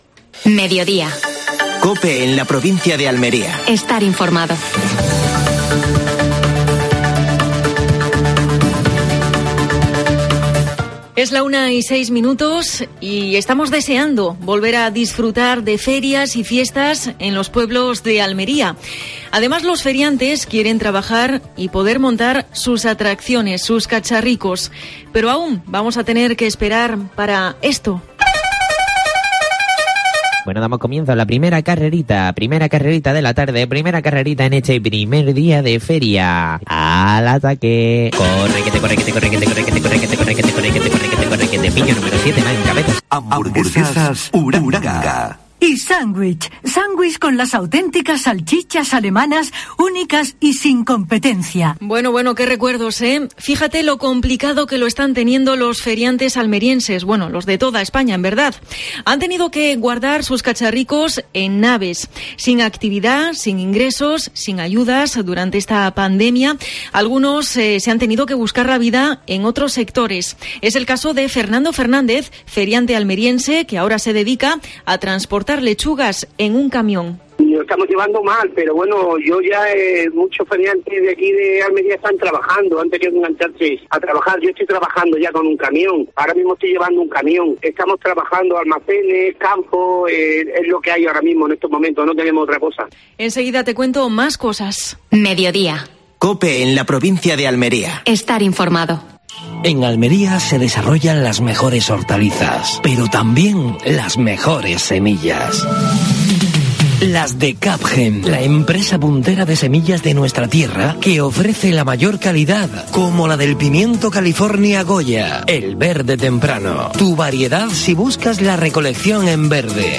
Actualidad en Almería. Entrevistas